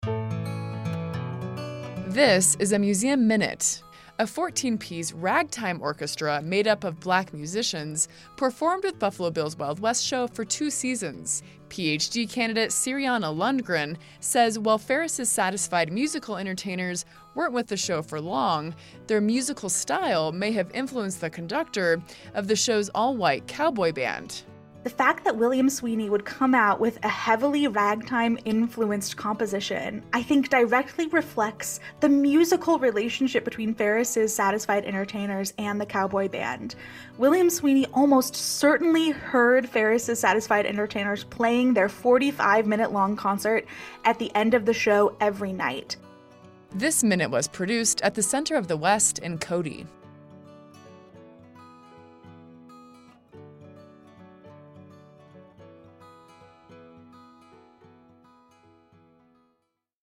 A 1-minute audio snapshot highlighting a museum object from the collection of the Buffalo Bill Center of the West.